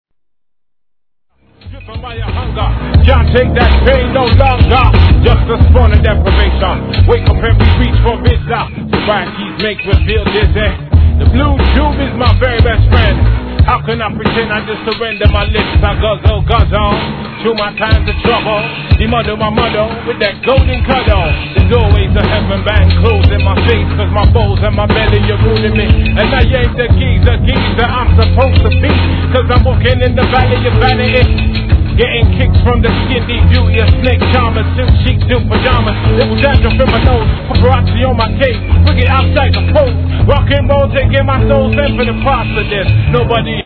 ドラムにシンセと混沌としたアブストラクトなブレークビーツが繰り広げる展開にぶっ飛ばされましょう!!